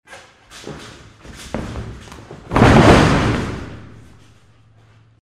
Звуки падения человека
Спортивные падения
Борец айкидо падает в зале